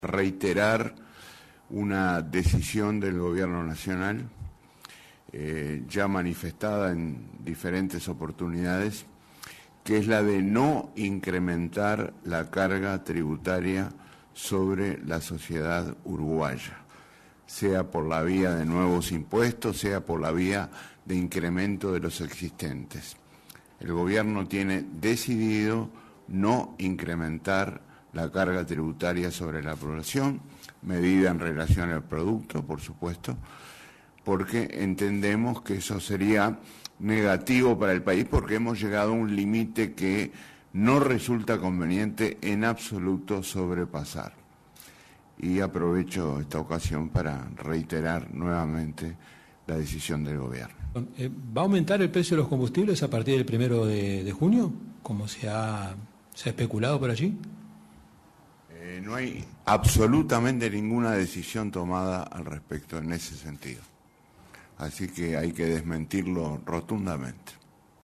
El ministro de Ganadería Enzo Benech dijo en conferencia de prensa luego del Consejo de Ministros, que desde enero hasta estas horas se han implementado más de 21 medidas para dinamizar el sector rural.